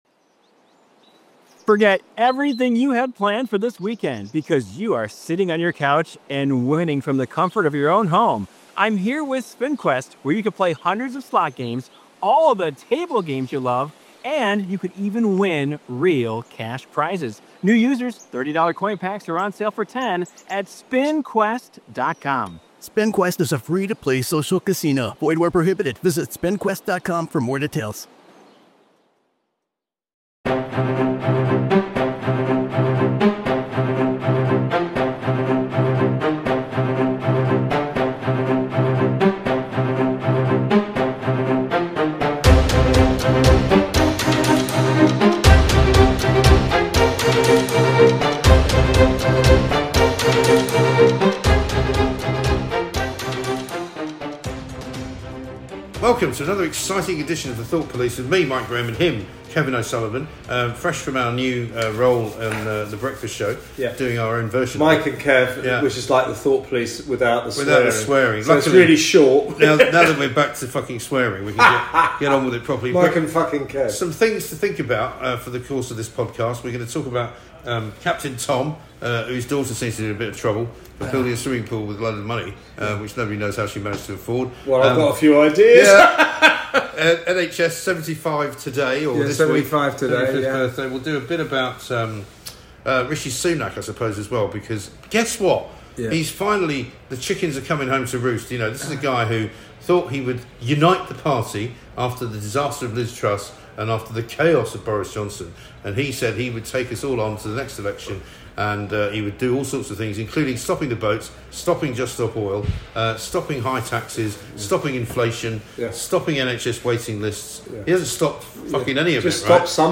The lads are back with another rage filled deep dive into the past weeks top stories, and the very worst...